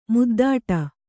♪ muddāṭa